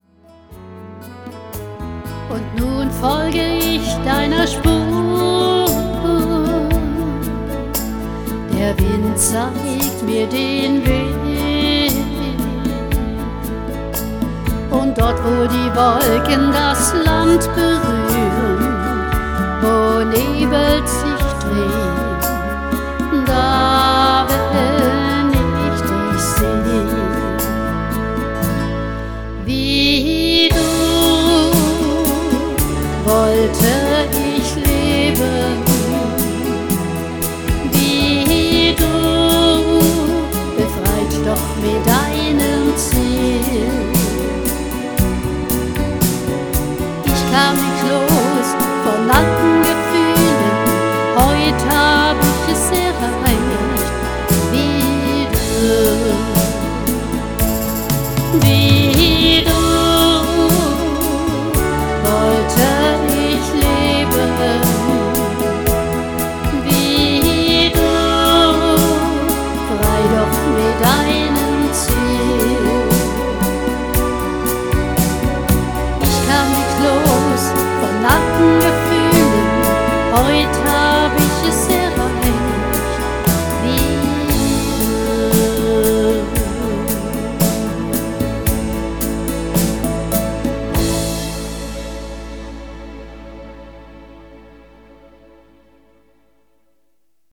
auf einem GENOS gespielt.
Bei einem Auftritt mit einem ZOOM H1n